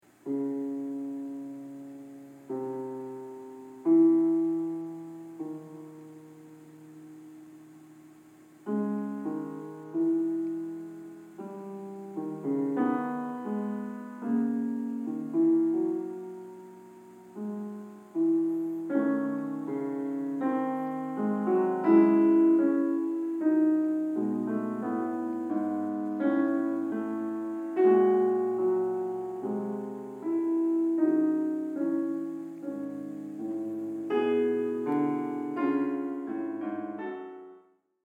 The above theme is a fugue by Bach, and it really does seem to describe the sorrow of Jesus on the cross, or him carrying the cross to his own execution: